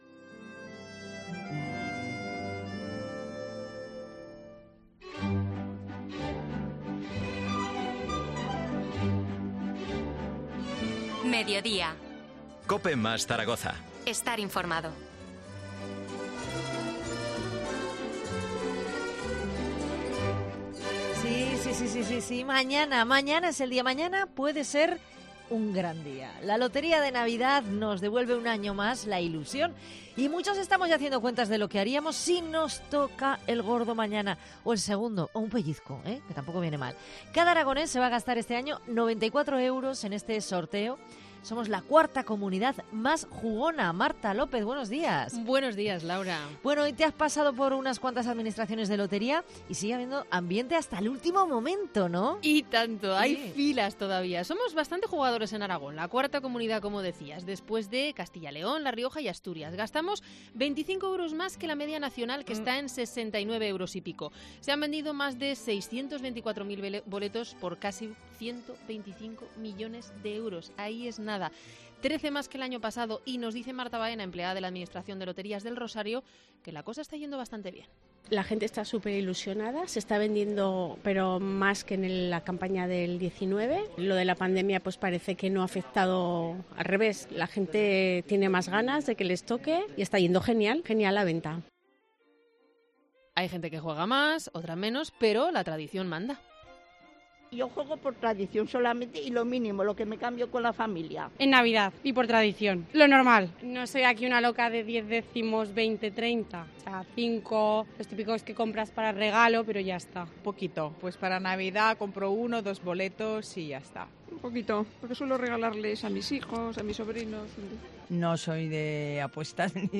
Así viven los aragoneses el Sorteo de Lotería de Navidad. Un reportaje